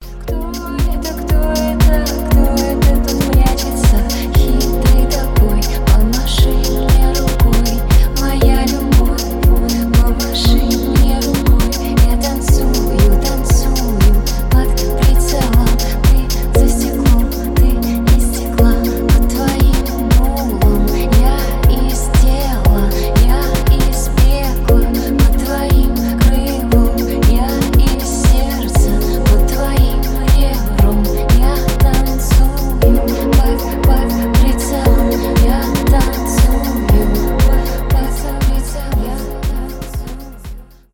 альтернатива
поп , атмосферные